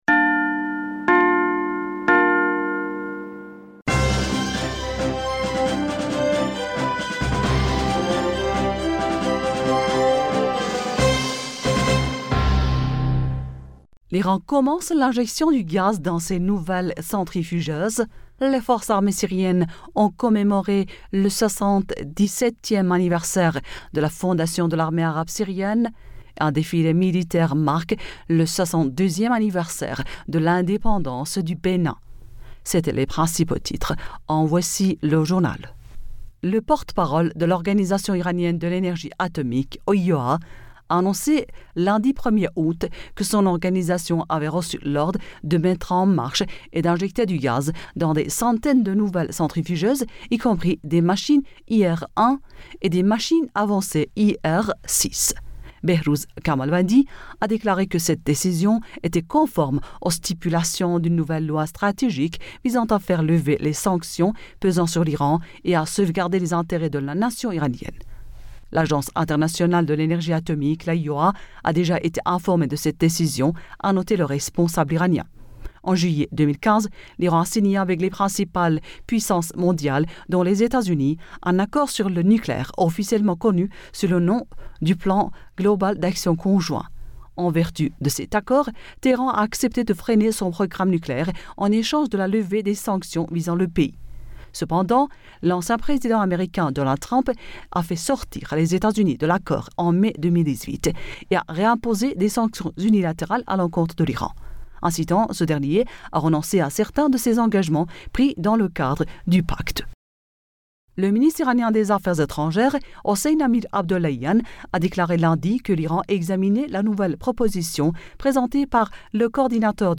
Bulletin d'information Du 02 Aoùt